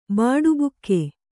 ♪ bāḍubukke